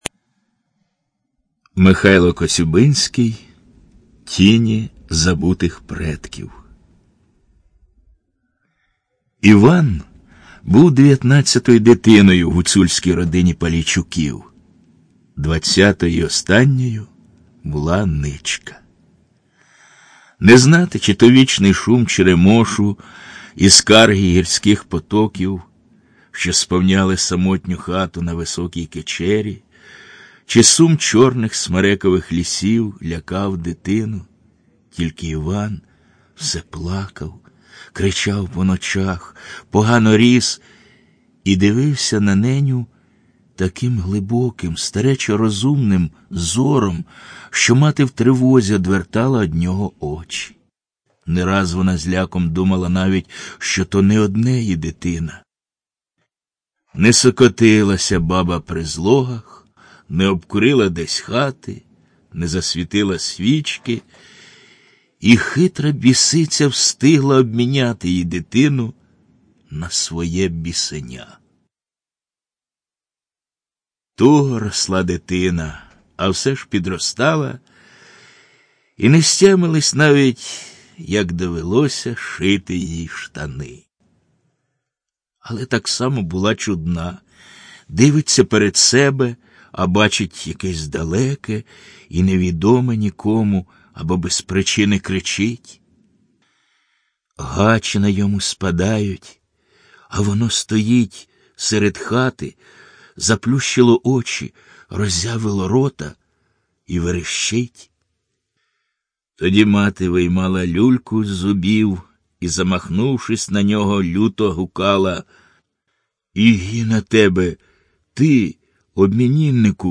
ЖанрКниги на языках народов Мира